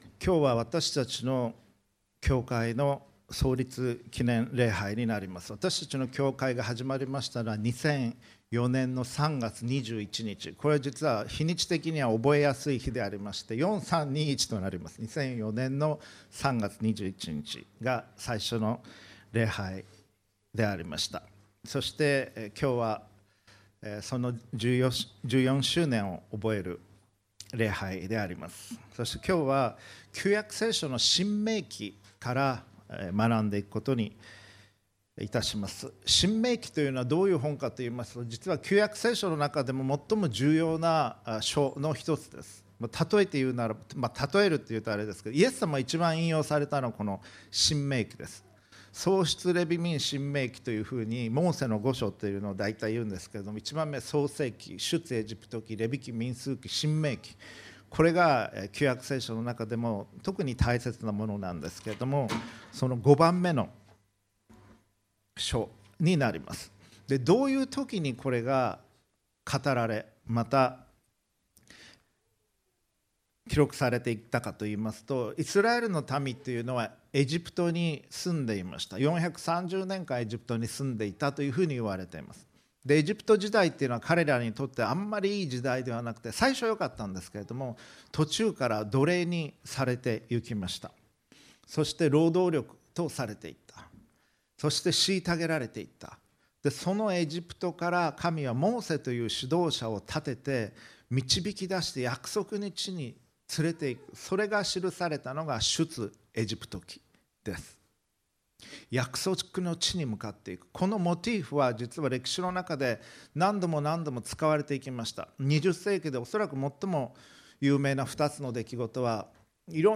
心に刻むべきこと”Something to keep in your heart” – 礼拝メッセージ – Podcast配信Feed –